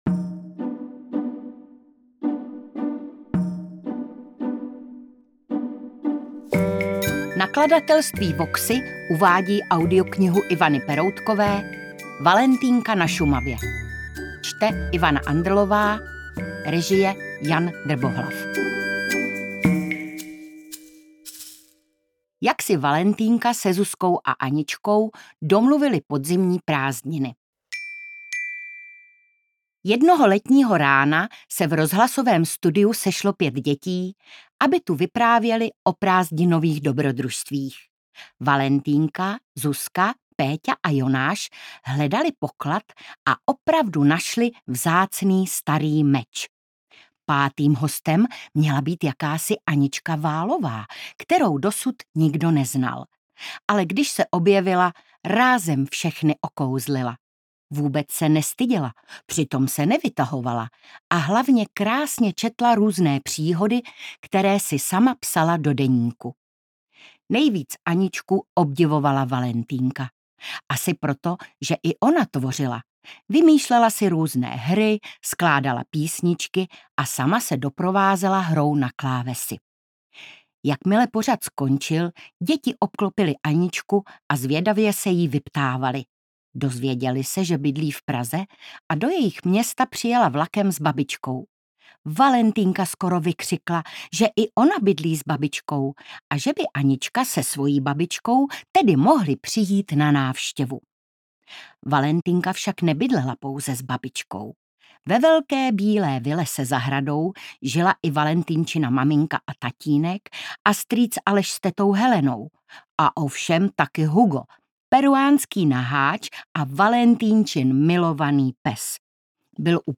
Čte: Ivana Andrlová
audiokniha_valentynka_na_sumave_ukazka.mp3